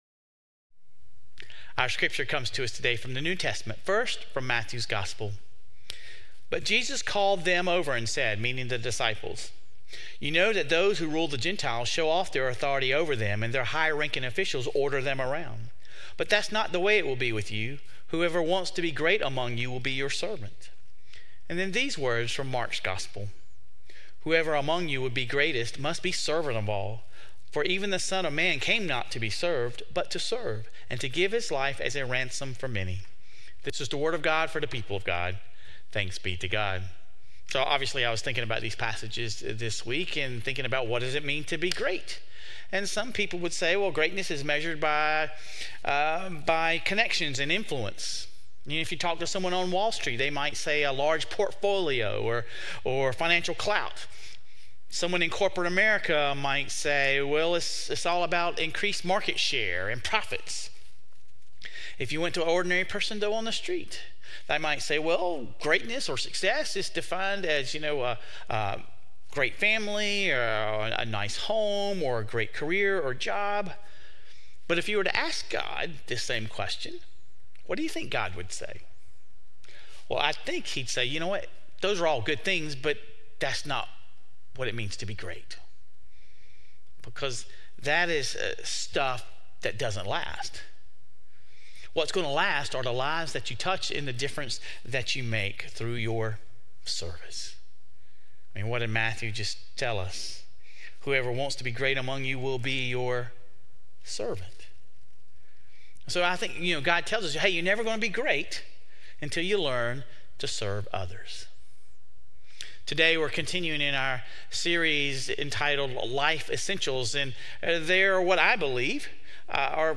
This week, we explore what it means to serve others. Sermon Reflections: